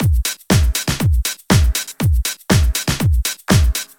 31 Drumloop.wav